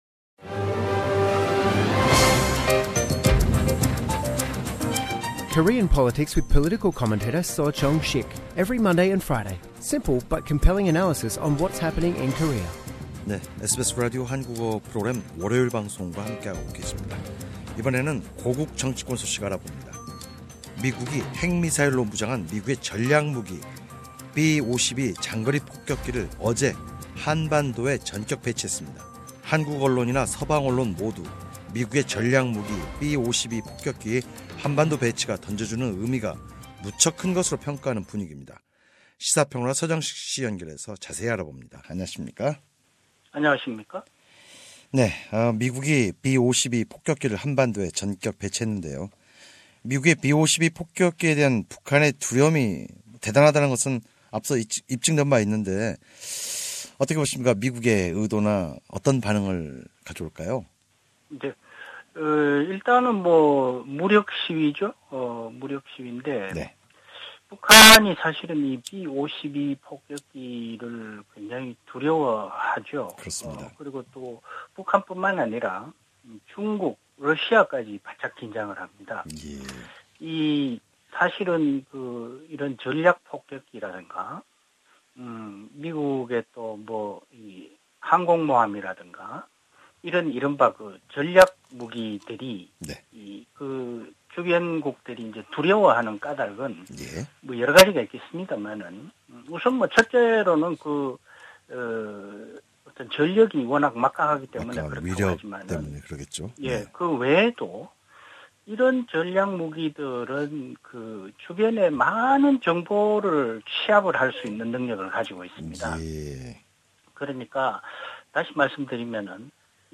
We will discuss further with Korean political commentator